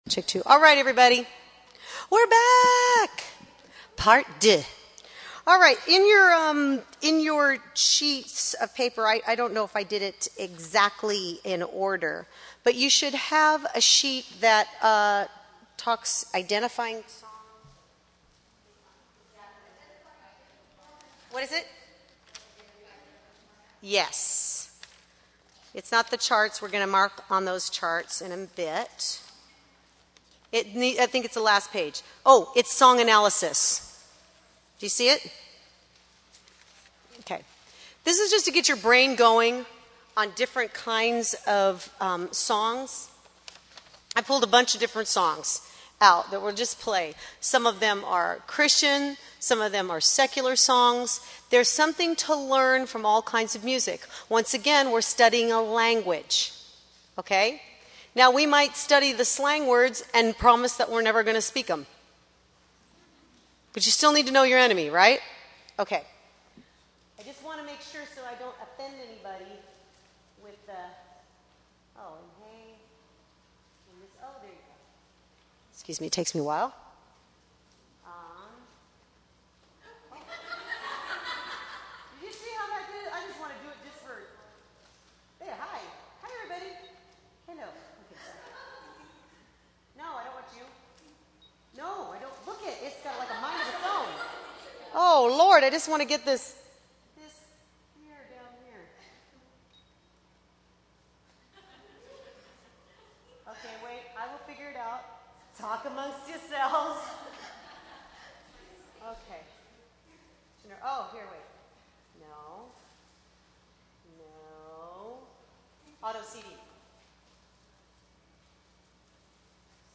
Series: 2007 Calvary Chapel Worship Leader Conference
From The 2007 Calvary Chapel Worship Life Conference themed Give Me Jesus.